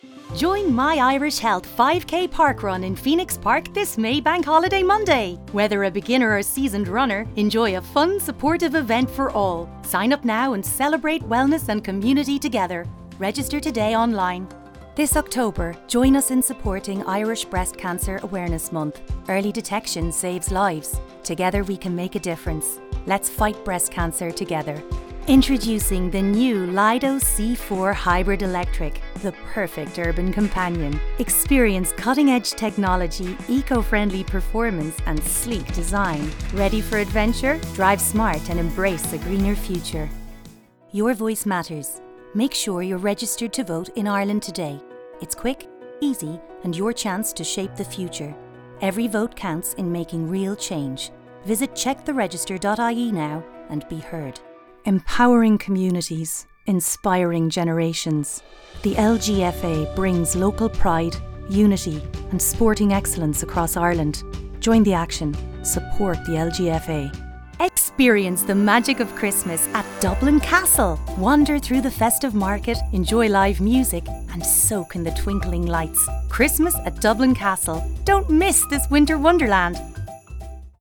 Female
RODE NT-1 kit with SM6 shock mount and pop filter, and Neewer Pro Microphone Isolation Shield, 3-Panel Pop Filter
30s/40s, 40s/50s
Irish Dublin Neutral, Irish Neutral, Irish West